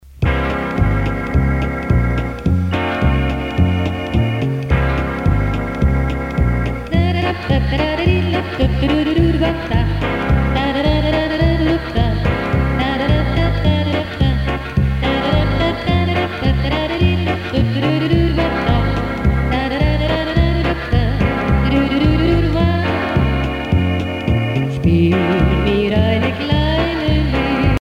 danse : charleston
Pièce musicale éditée